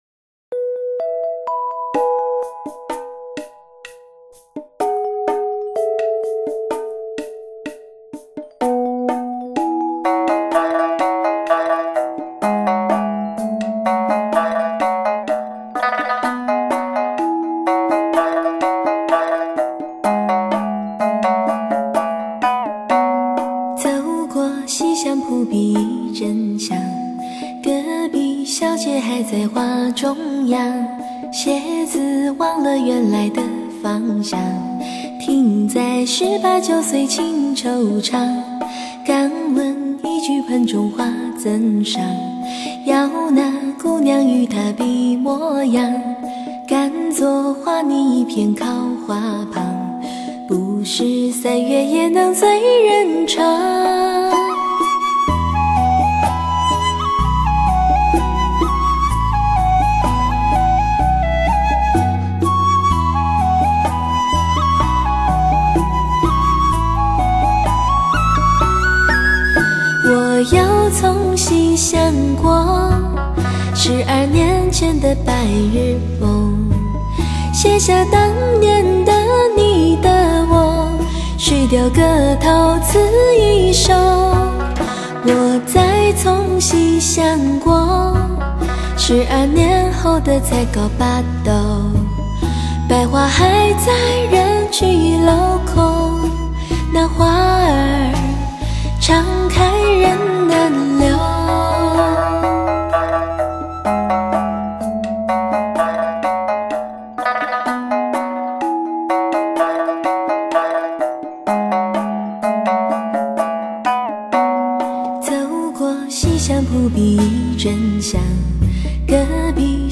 （试听为低品质wma，下载为320k/mp3）